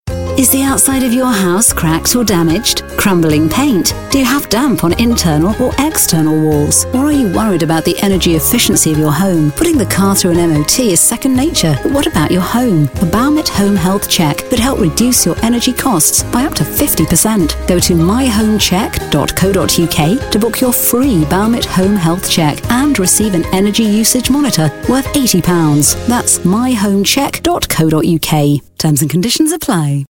Baumit Home Health Check - kmfm commercial